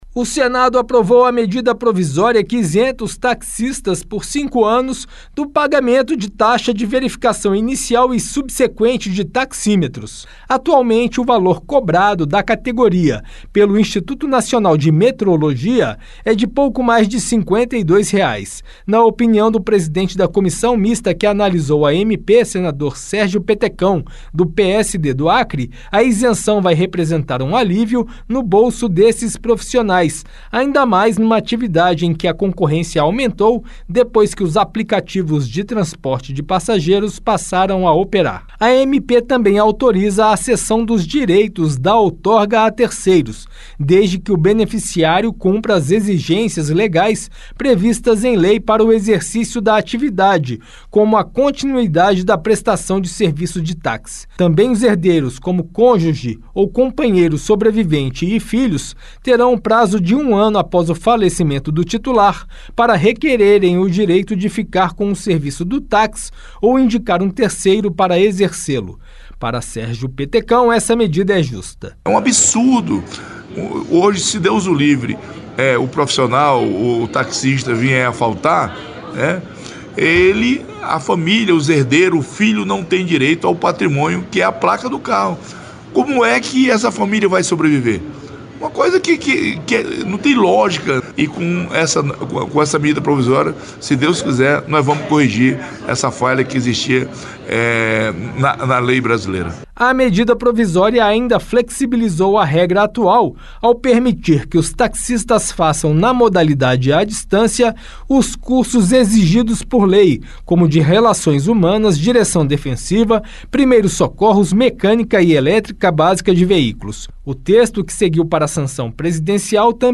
Senador Sérgio Petecão